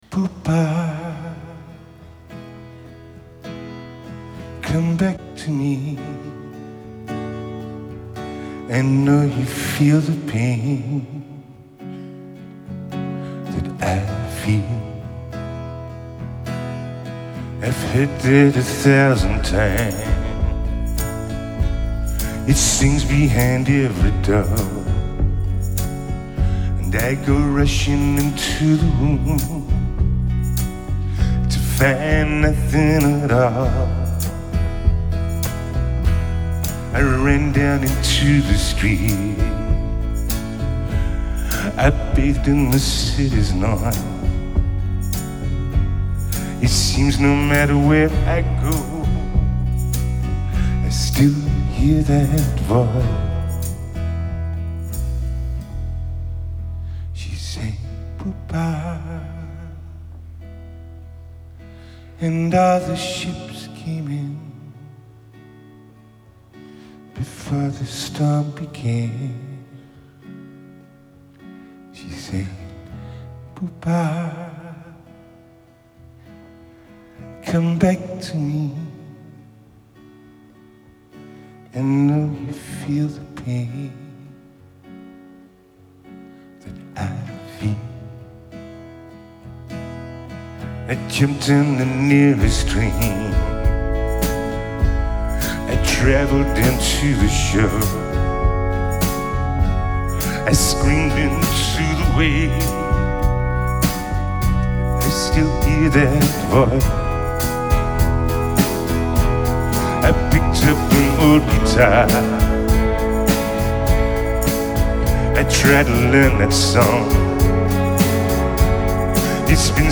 Genre: Alternative, Indie Rock, Chamber Pop